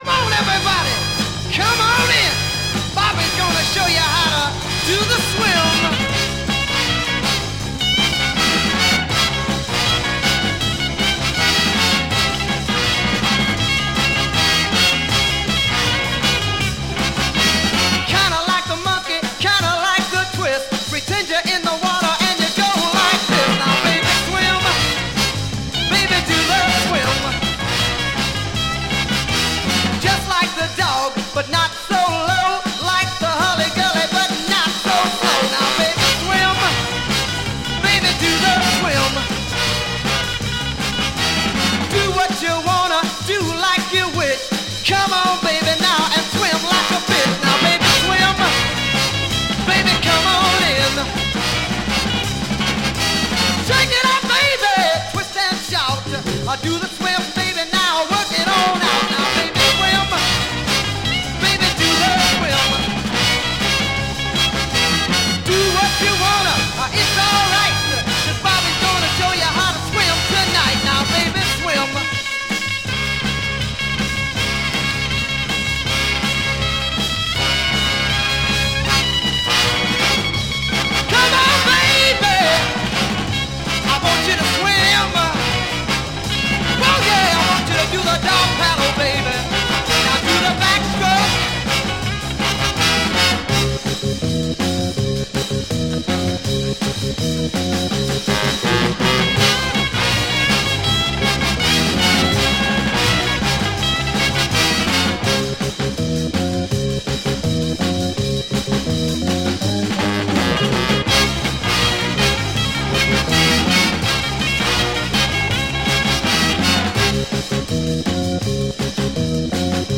Killer R&B Soul smasher Mod EP!
Mega rare French EP issue, Killer R&B Mod smasher!!